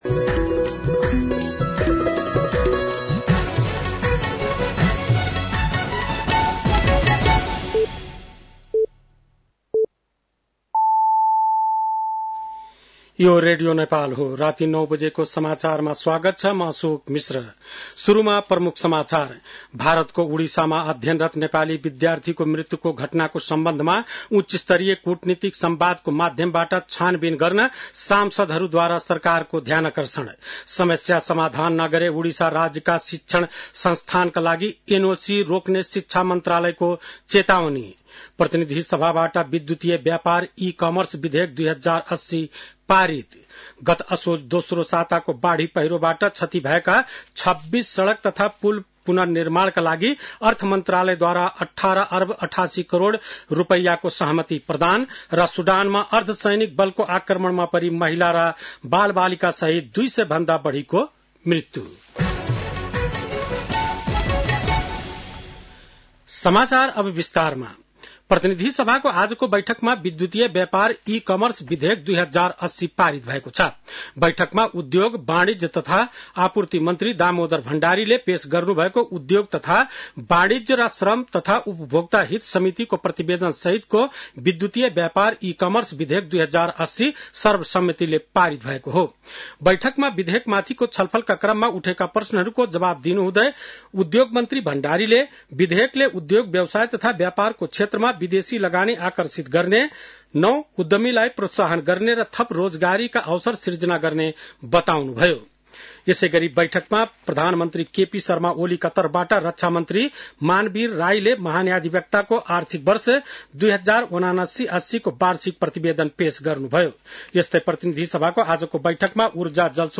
An online outlet of Nepal's national radio broadcaster
बेलुकी ९ बजेको नेपाली समाचार : ७ फागुन , २०८१